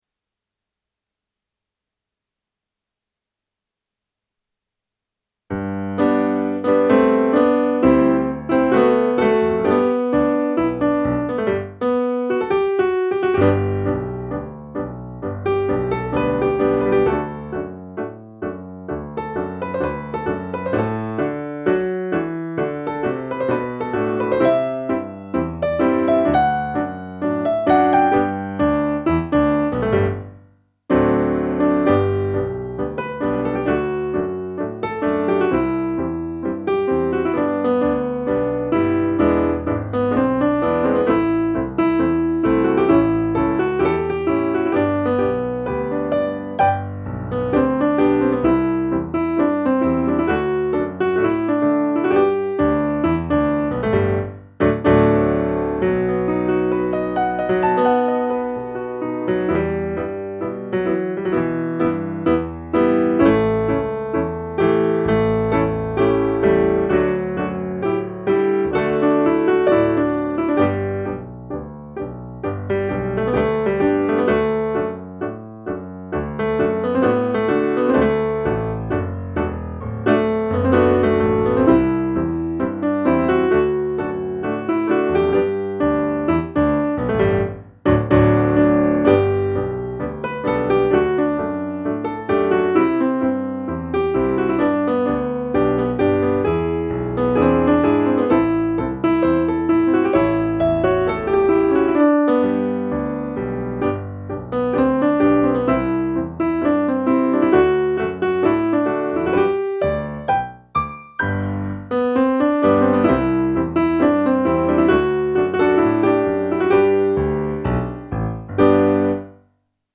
TANGO
Tango Piano) L'ADIEU